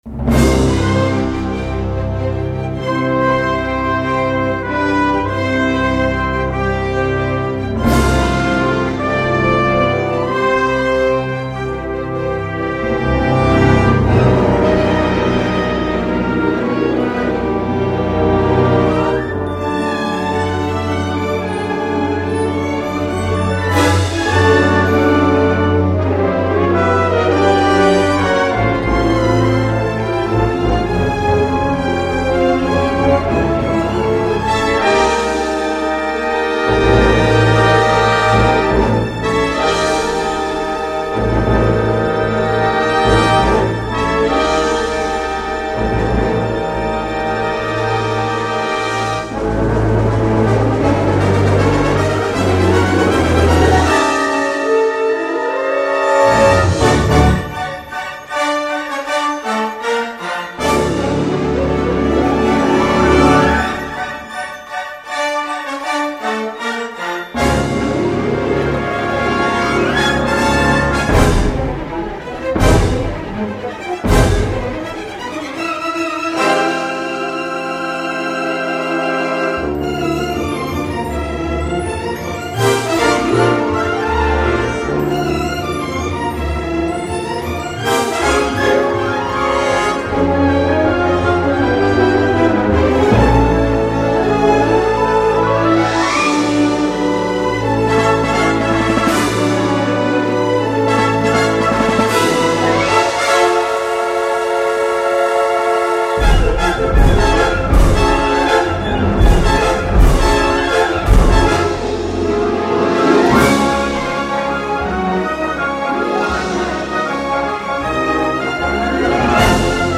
It was very classical and sounded like a big budget movie.
(The launch would kick in about 55 seconds into it!)